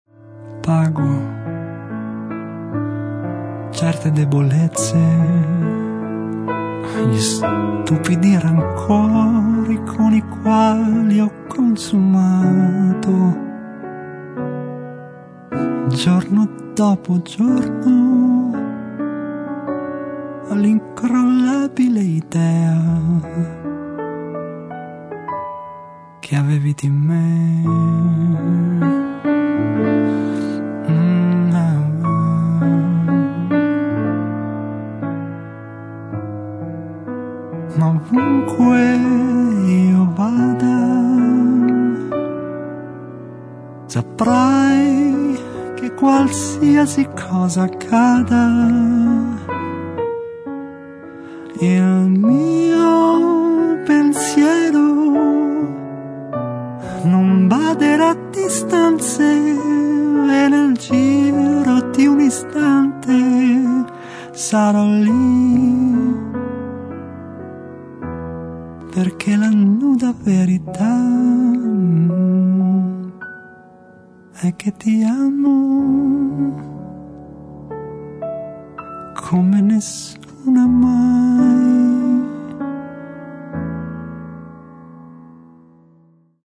registrato e missato alla
chitarre acustiche e classiche